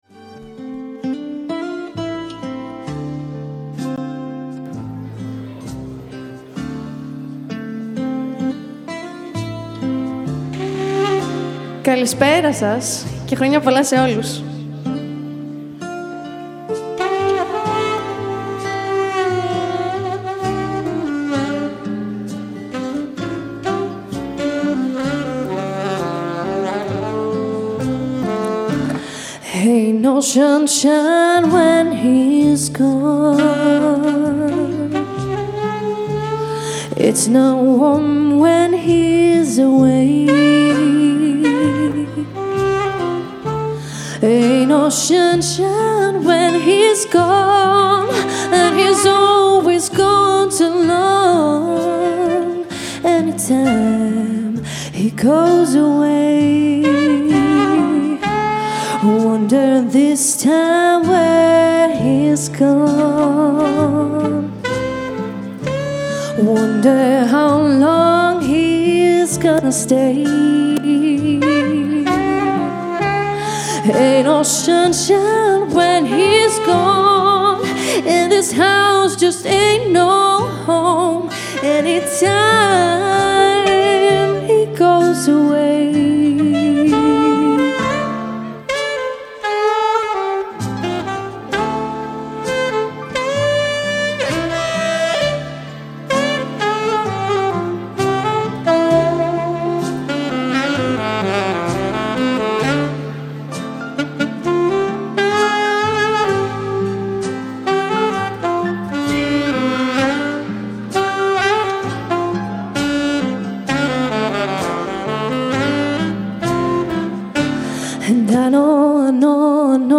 vocal sax live rec